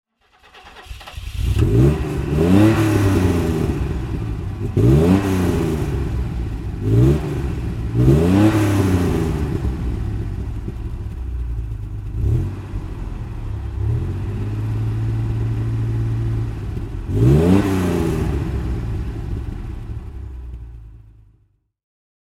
You can even hear the car!
Fiat 130 Coupé (1974) - Starten und Leerlauf